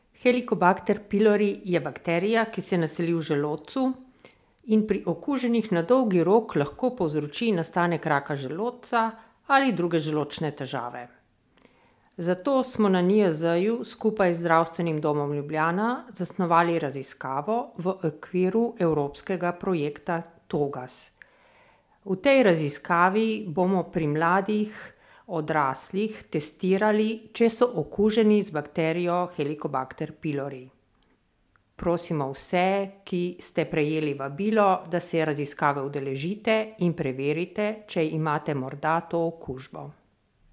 Zvočna izjava